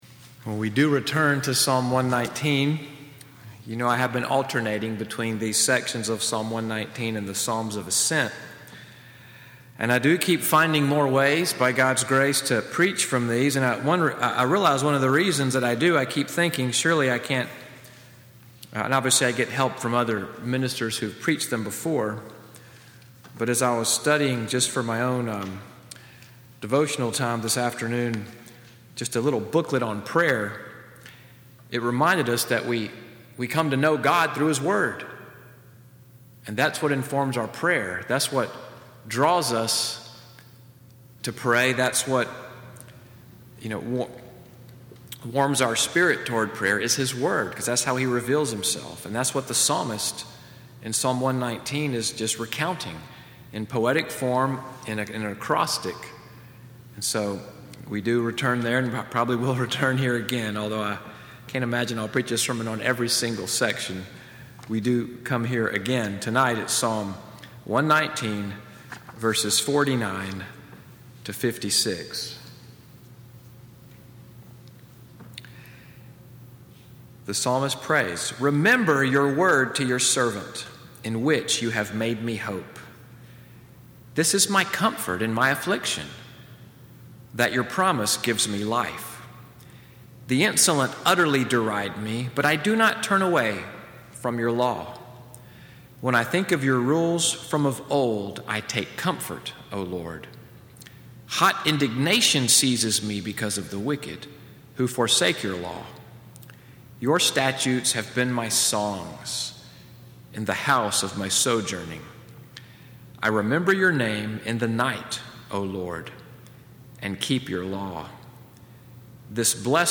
Evening Worship at NCPC-Selma, audio from the sermon, “Finding God’s Comfort,” (23:32), May 27, 2018.